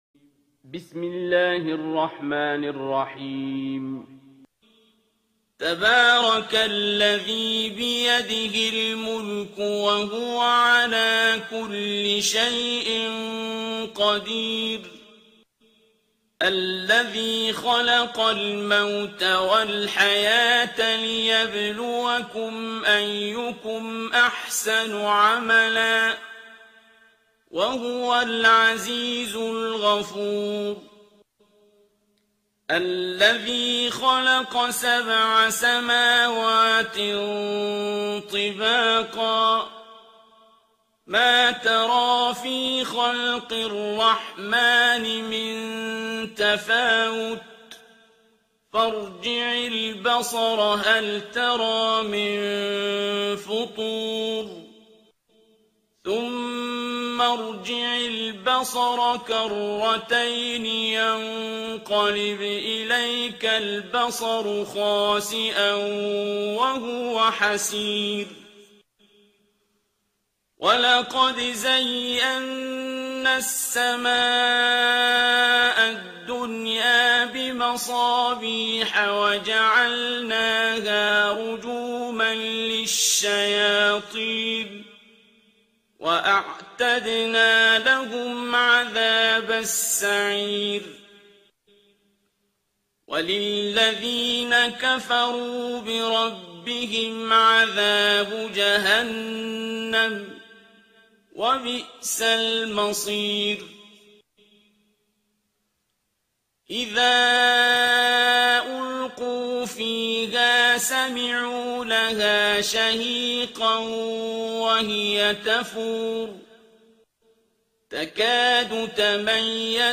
ترتیل سوره ملک با صدای عبدالباسط عبدالصمد